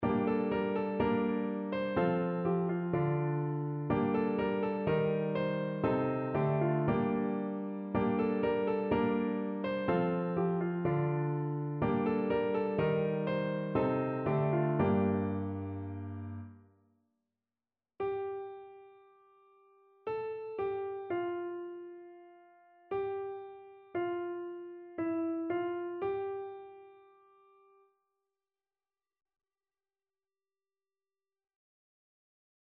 messe-de-saint-andre-alleluia-satb.mp3